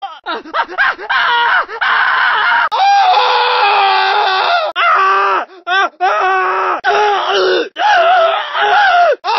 gta-san-andreas-pedestrian-voices-coughing-pain-male-audiotrimmer_yMs9pKQ.mp3